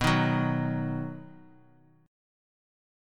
B Chord
Listen to B strummed